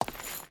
Stone Chain Walk 1.ogg